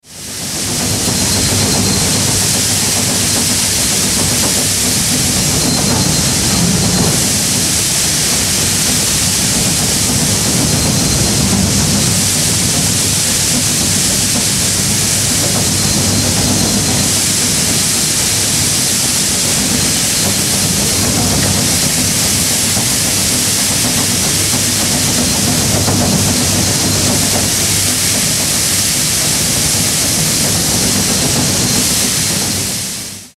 099. Елеватор з зерном пшениці.
99.-elevator-z-zernom-pshenyczi.mp3